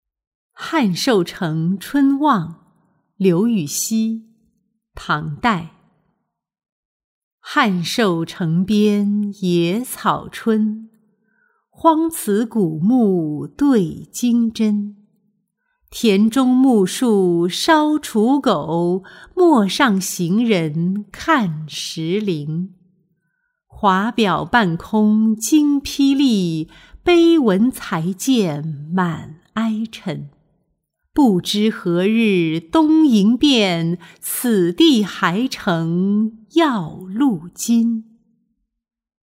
汉寿城春望-音频朗读